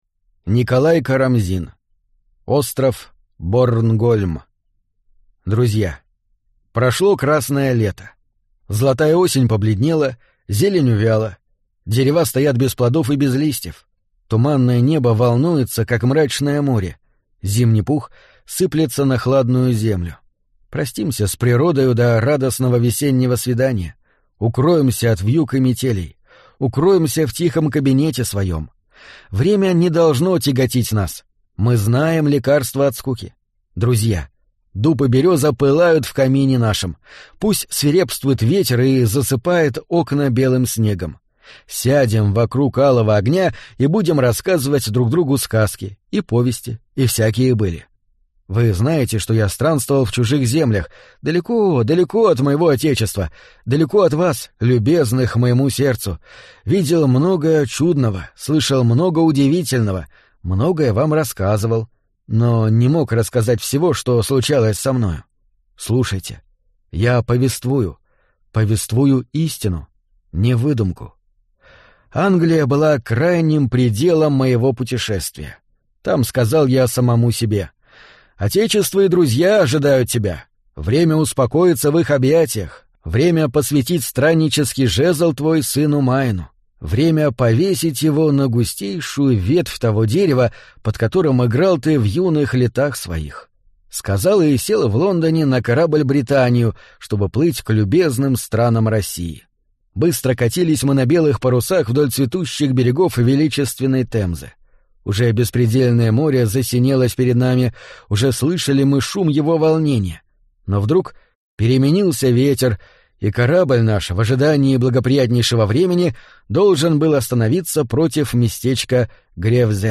Аудиокнига Черный монах и другие ужасы | Библиотека аудиокниг